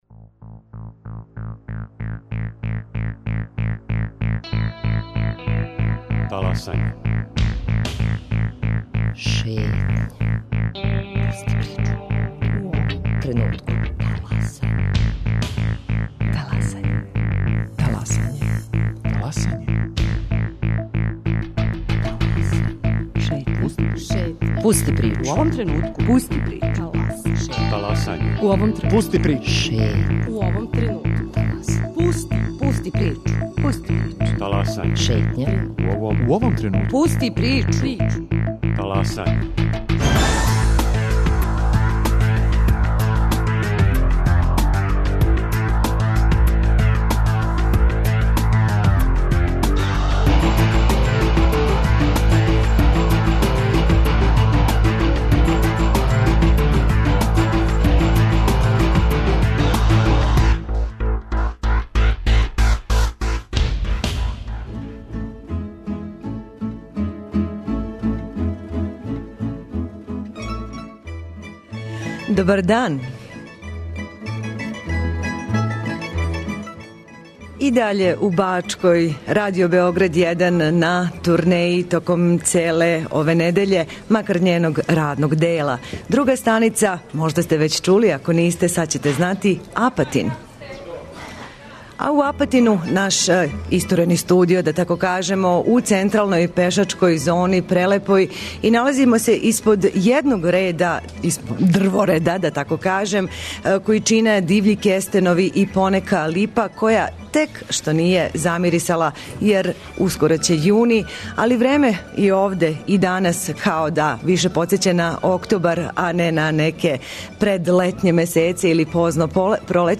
Настављамо пролећну турнеју Радио Београда 1 - данас смо у Апатину!